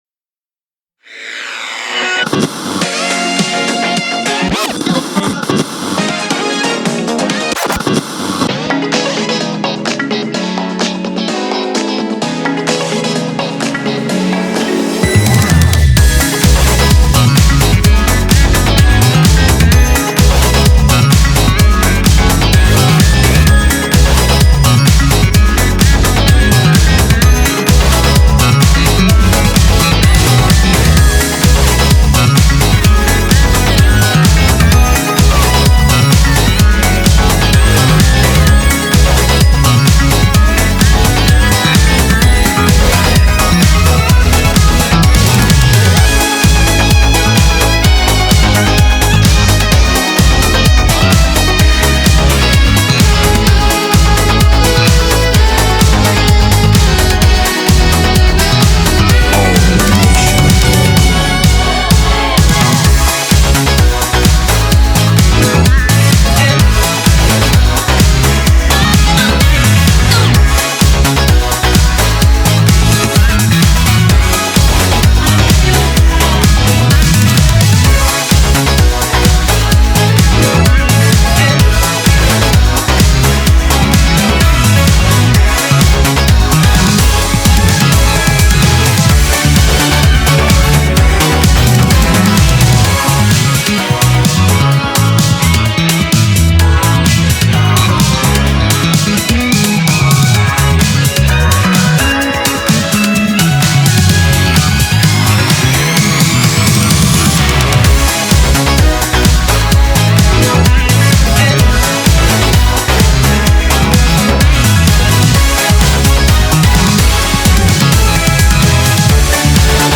BPM128
Audio QualityPerfect (High Quality)
Commentaires[NU 80's DISCO FUNK]